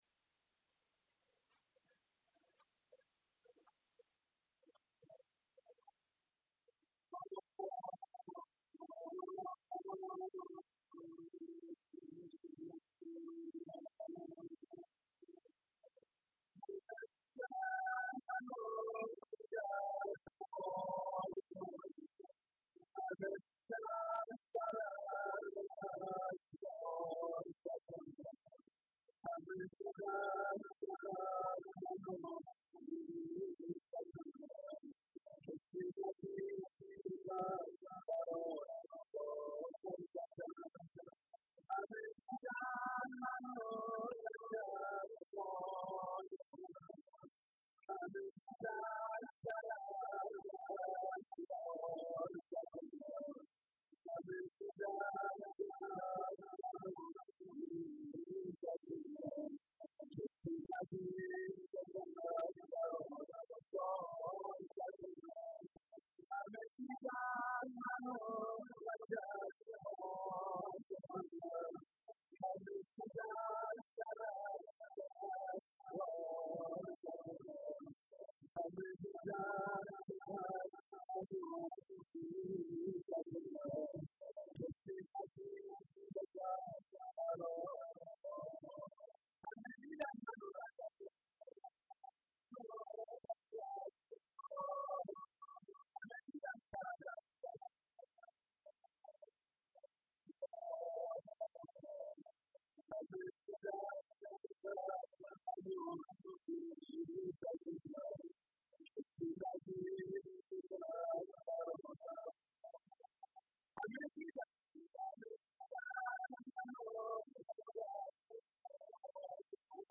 روضه و ذکر